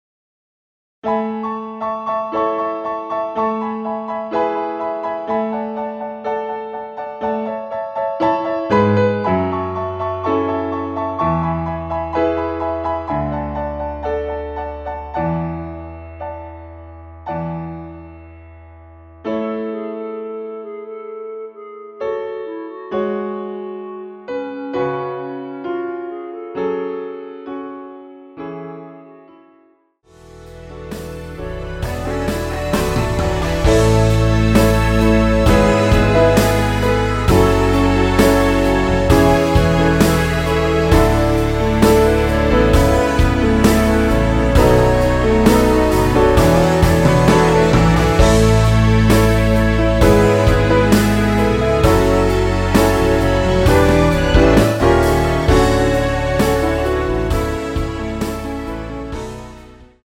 남자키에서 (-2) 더 내린 멜로디 포함된 MR 입니다.(미리듣기 참조)
앞부분30초, 뒷부분30초씩 편집해서 올려 드리고 있습니다.
중간에 음이 끈어지고 다시 나오는 이유는